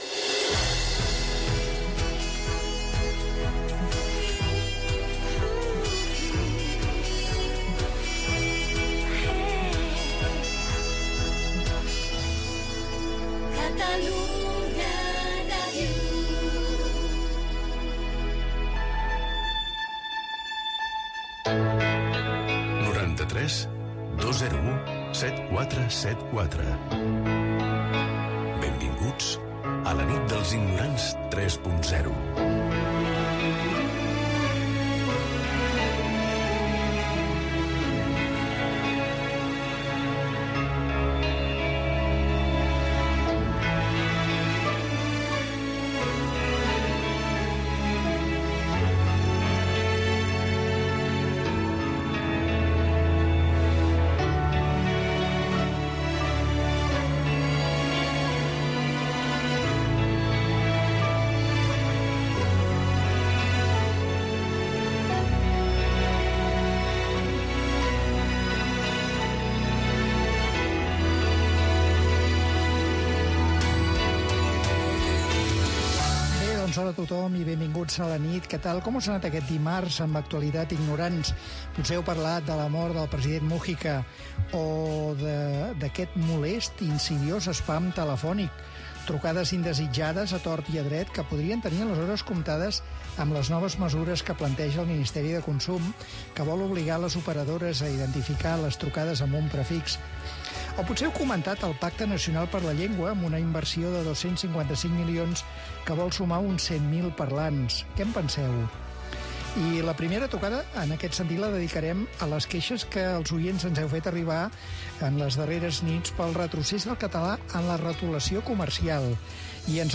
Indicatiu de l'emissora, telèfon de participació i careta del programa, salutació i repàs a l'actualitat, "dites fites", tema musical, queixa d'una oidora pel retrocés de la retolació en català
Gènere radiofònic Participació